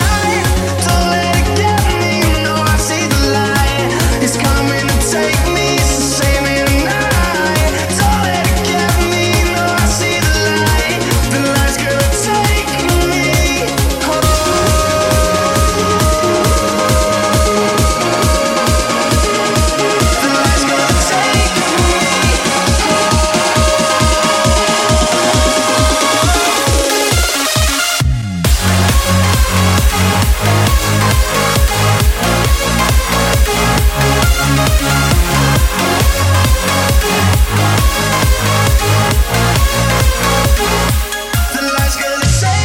Genere: dance, edm, club, remix